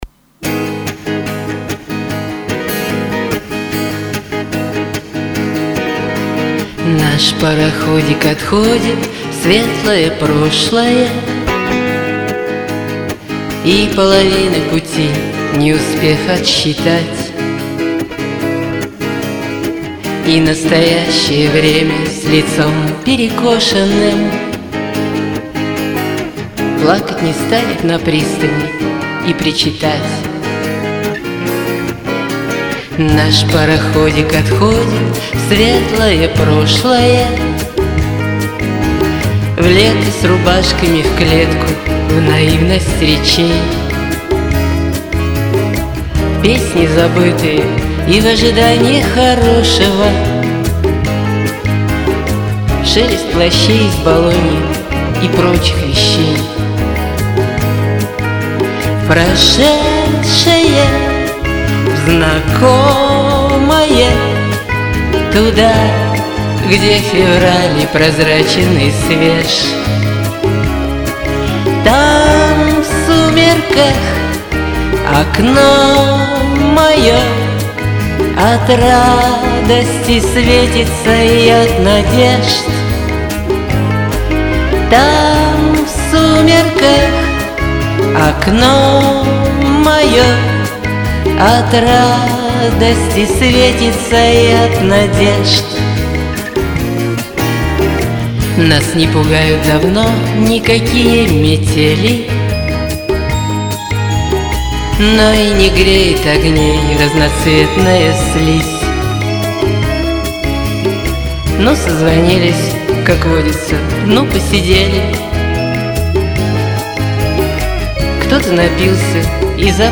Чем понравились - оба чистенько.
более камерно